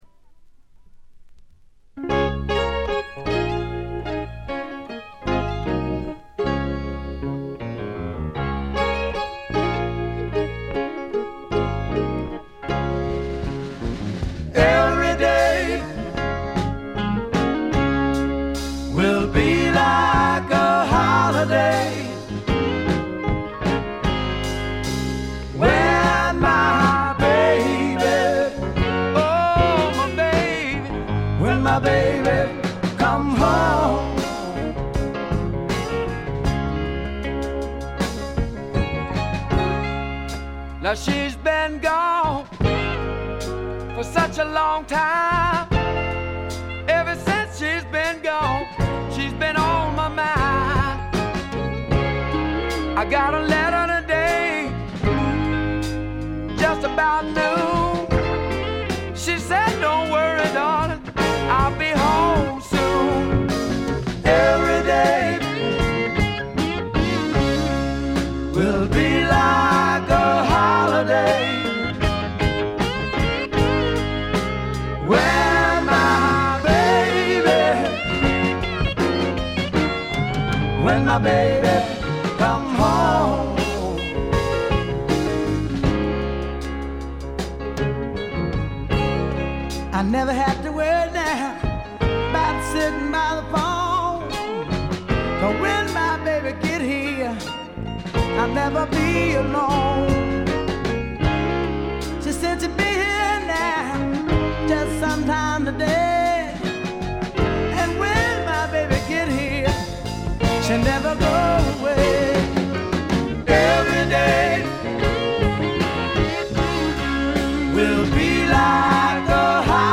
これ以外は軽微なバックグラウンドノイズ程度。
びしっと決まった硬派なスワンプ・ロックを聴かせます。
試聴曲は現品からの取り込み音源です。
Recorded at Paramount Recording Studio.